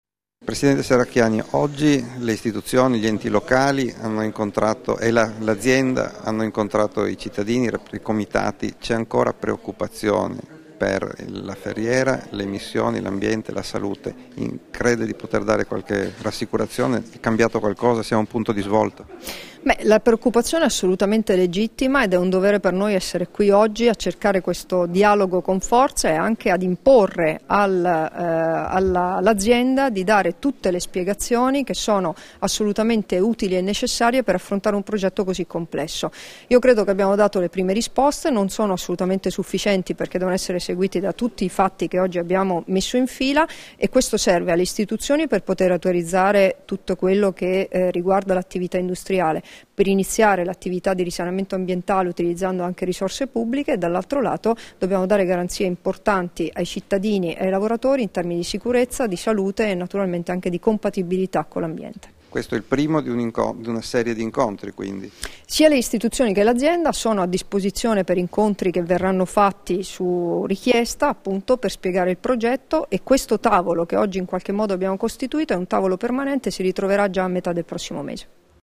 Dichiarazioni di Debora Serracchiani (Formato MP3) [1258KB]
all'illustrazione del progetto di messa in sicurezza, riconversione industriale e sviluppo produttivo dello stabilimento della Ferriera di Servola (TS) alle associazioni ambientaliste, rilasciate a Trieste il 13 febbraio 2015